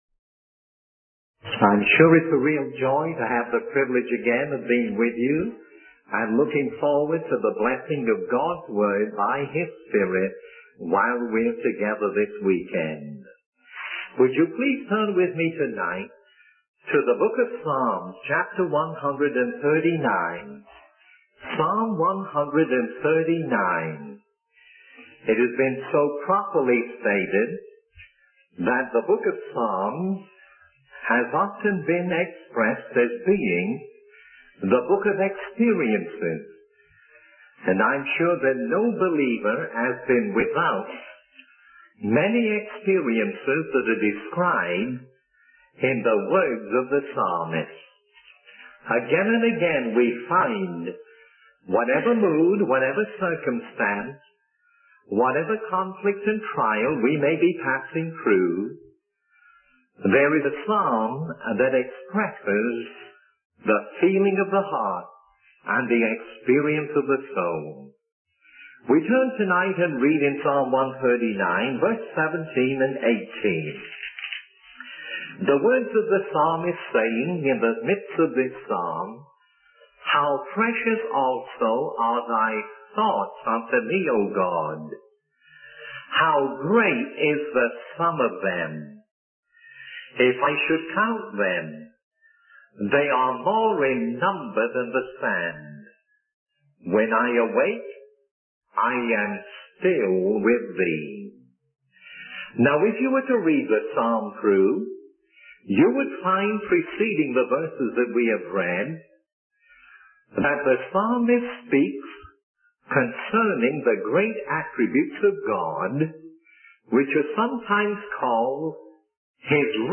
In this sermon, the preacher emphasizes the importance of sowing the seeds of the word of God in the world.